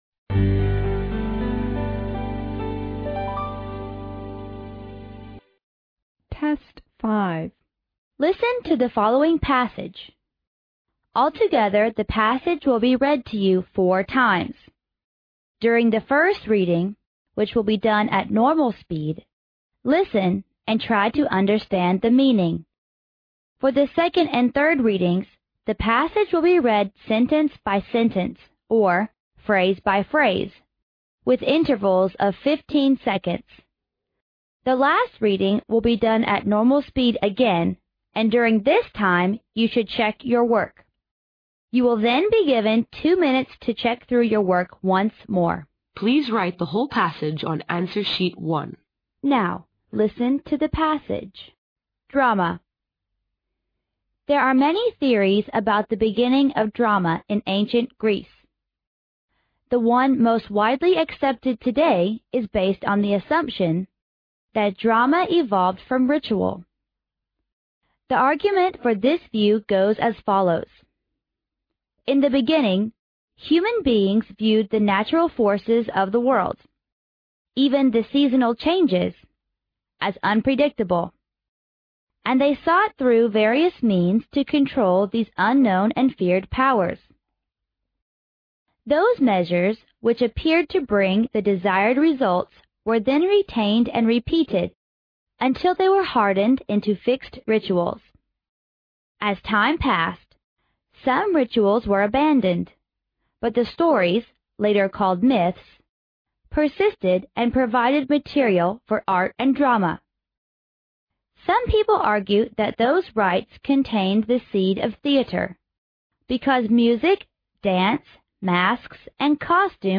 Altogether the passage will be read to you four times.
The last reading will be done at normal speed again and during this time you should check your work.